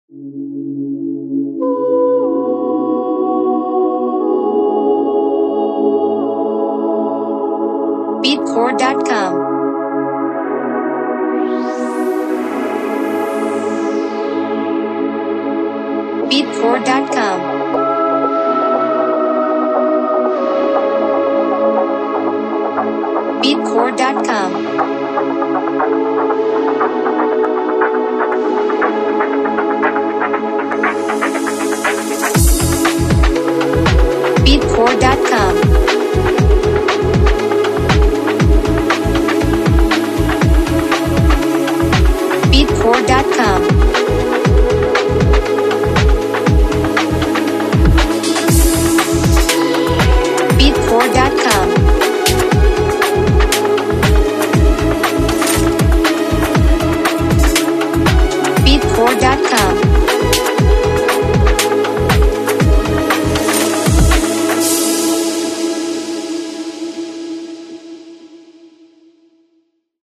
Genre: Garage Mood: Calm Travel BPM: 119 Time Signature: 4/4
Instruments: Synthesizer Vocal